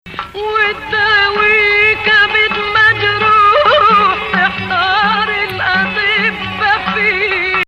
Nah. 5
strong emphasis on flat-7